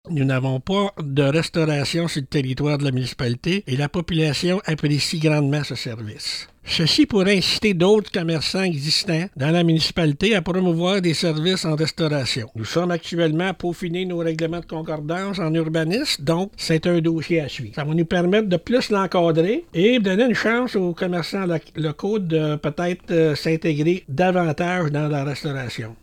La Municipalité de Blue Sea annonce la reconduction, pour une deuxième année consécutive, du projet pilote 2025 des camions cuisine de rue. Le maire, Yvon Blanchard, souligne que cette initiative connaît un succès notable, particulièrement en raison de la carence en services de restauration sur le territoire.